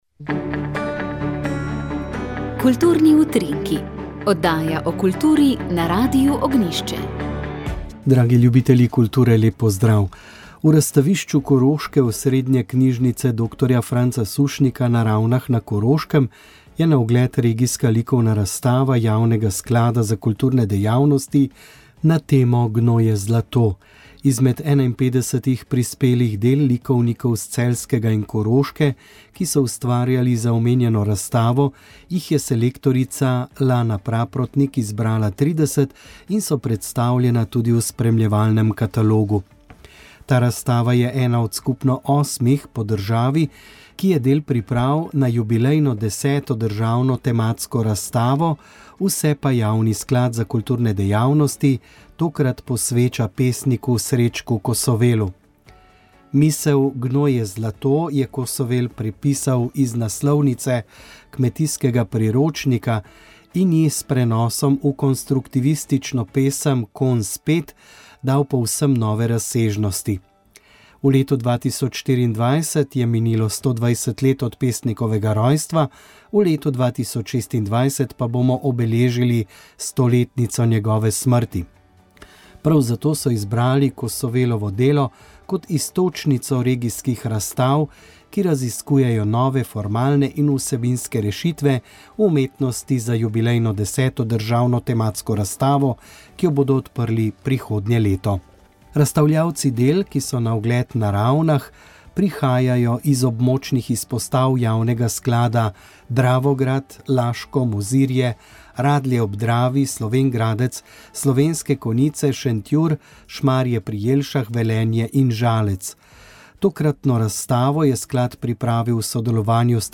Radijska kateheza